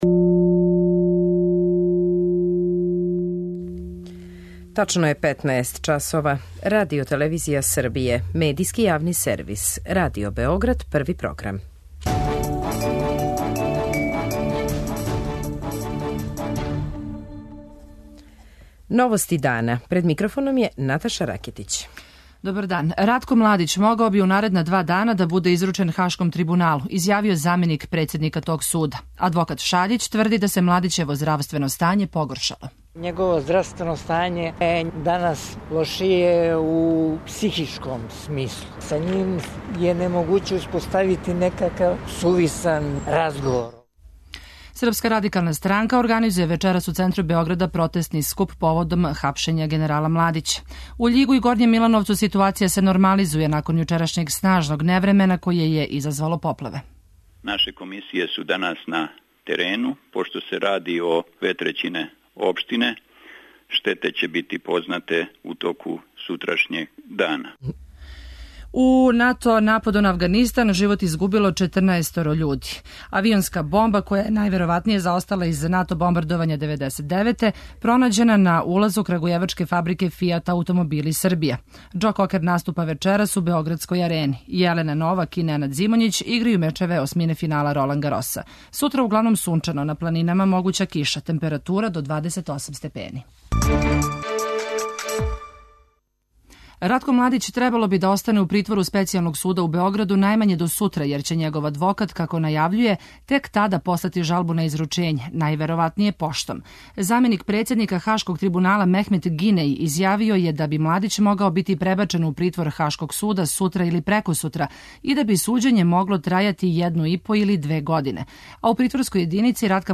О овим поплавама за Новости дана говоре начелник сектора за ванредне ситуације МУП-а Србије Предраг Марић и председник општине Горњи Милановац Милисав Мирковић.
преузми : 14.54 MB Новости дана Autor: Радио Београд 1 “Новости дана”, централна информативна емисија Првог програма Радио Београда емитује се од јесени 1958. године.